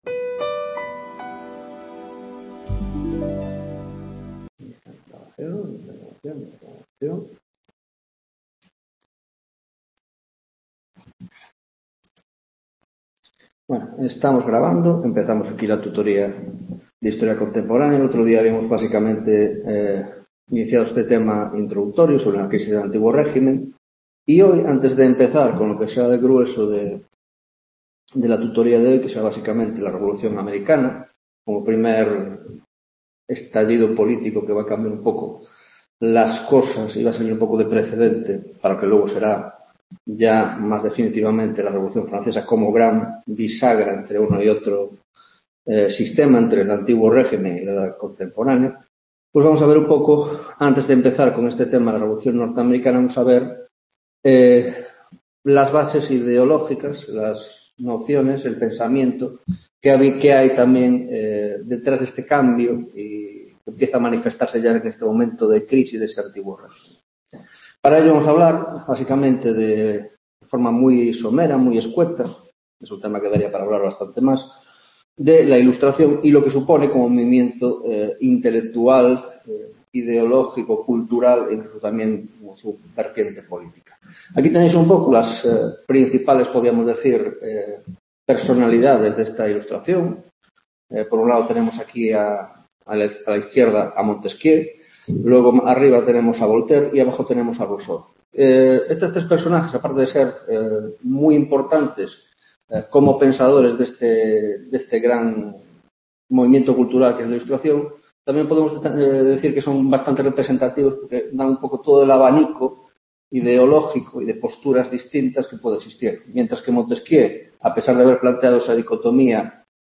2ª Tutoría de Historia Contemporánea - Introducción: .La Crisis del Antiguo Régimen (Ilustración) y Revolución Americana